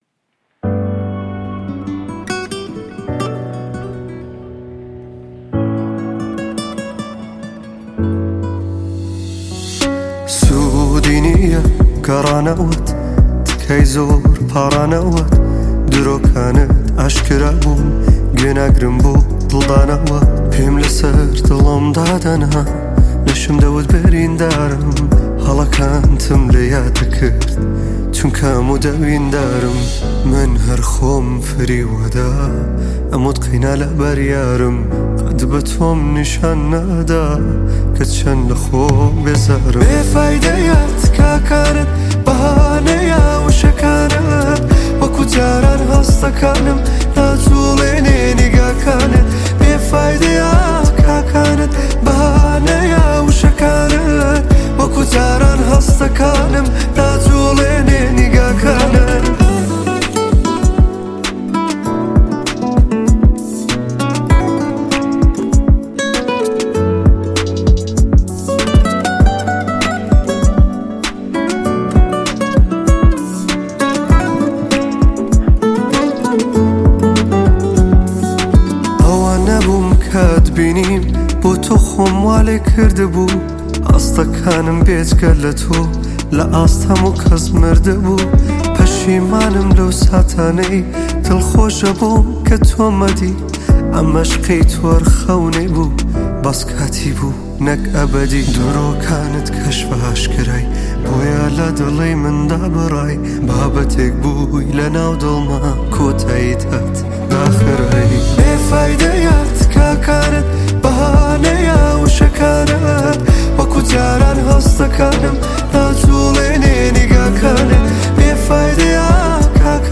تک اهنگ کردی